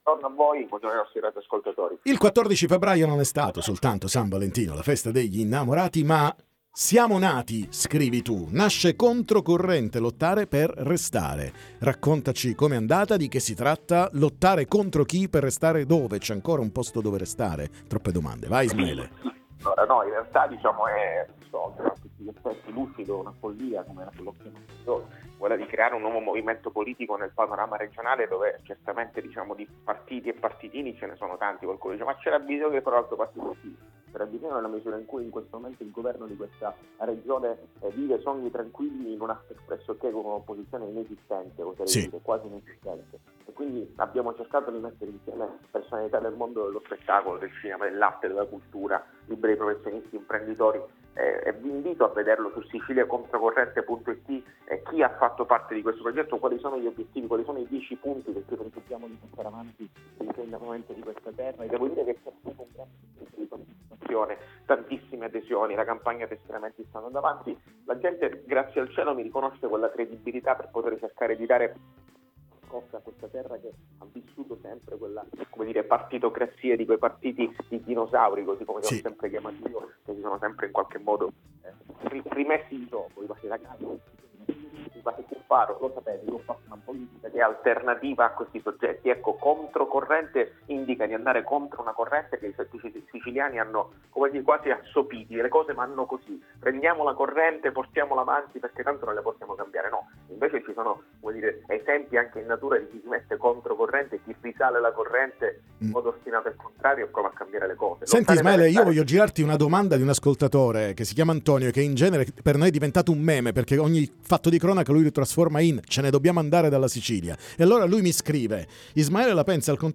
L’ex Iena Ismaele La Vardera lancia il suo movimento politico Controcorrente, ne parliamo con lui ai ns. microfoni.